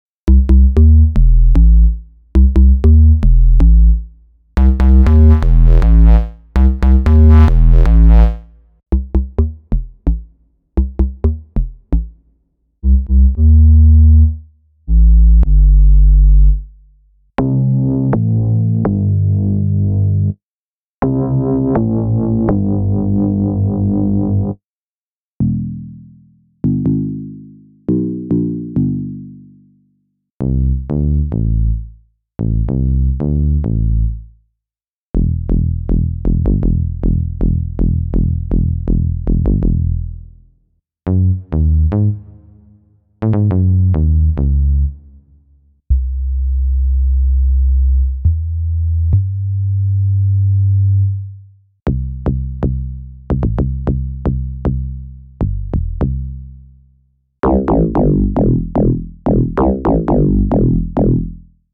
Elektron Gear Model:Cycles
Basses